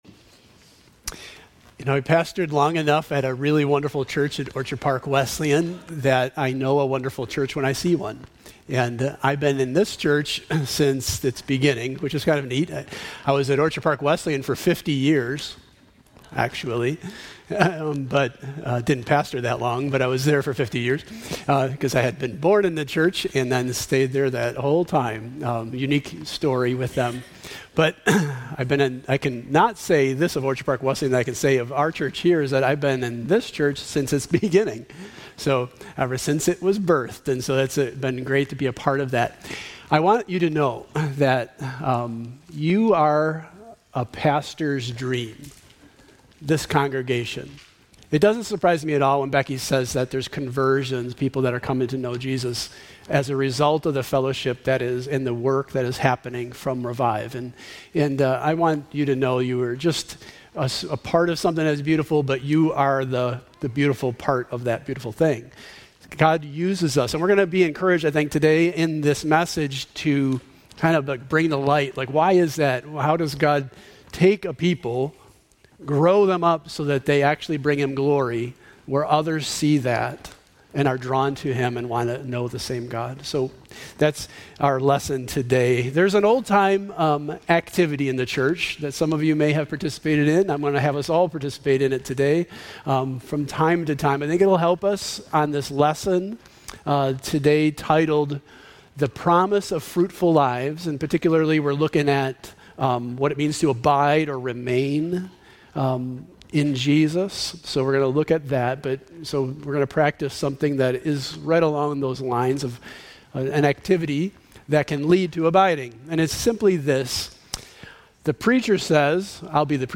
God wants us to follow Him wholeheartedly in every season of our lives. Listen Go Deeper Sermon Discussion Guide To Be Continued... reading plan More VIEW MORE SERMONS